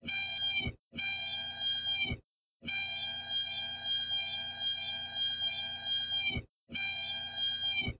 stall2.wav